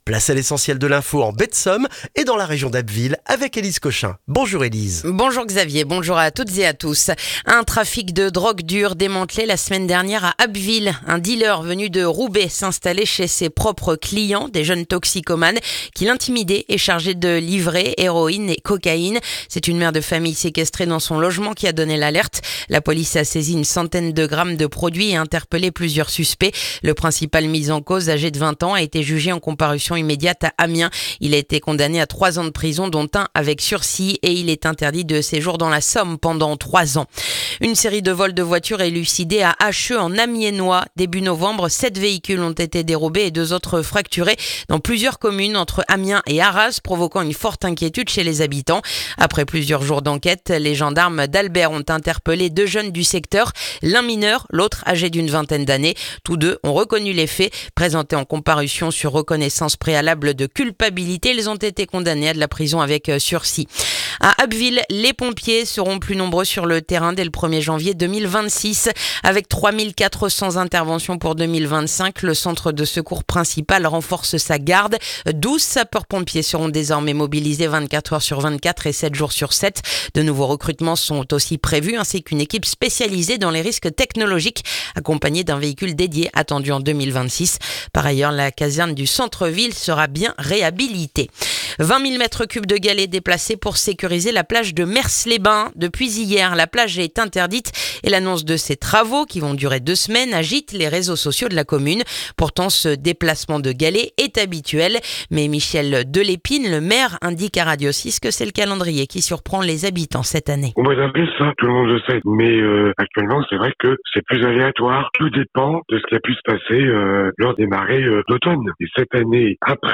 Le journal du mardi 2 décembre en Baie de Somme et dans la région d'Abbeville